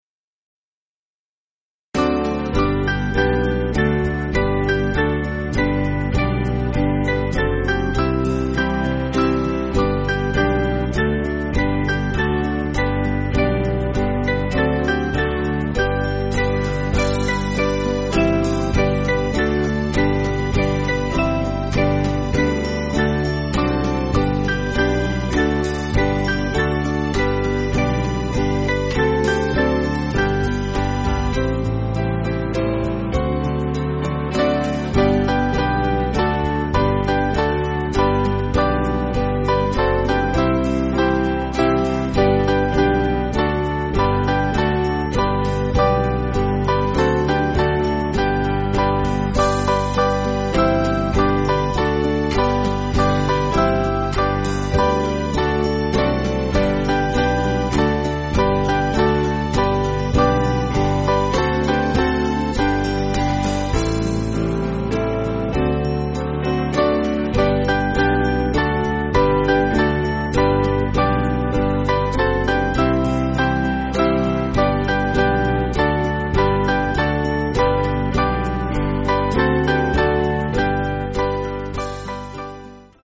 8.7.8.7.D
Small Band
(CM)   3/G